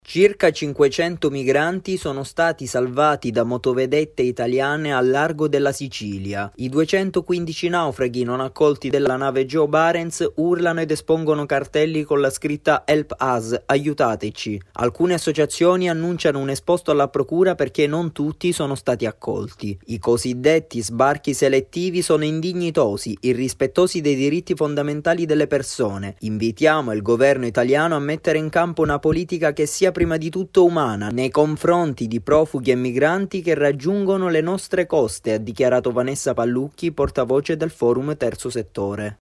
Servizio-Grs-Diritti-8-novembre.mp3